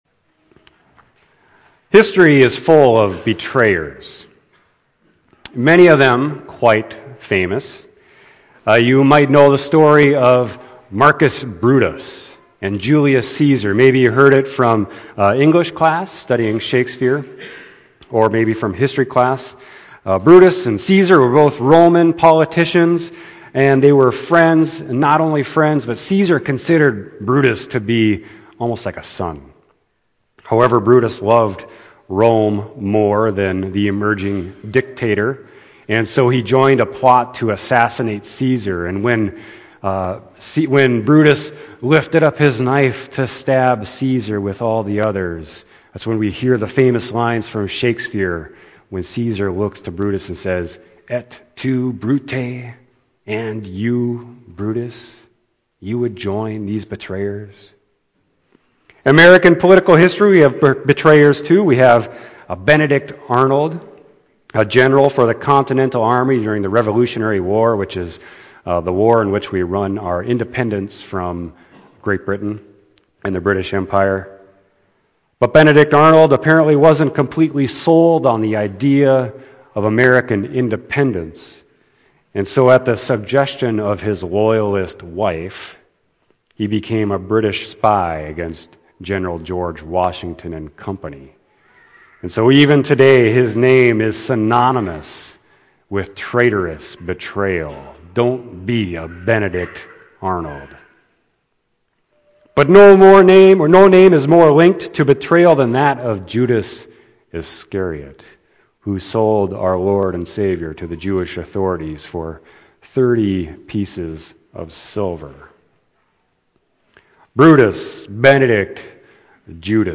Sermon: Luke 24.36-48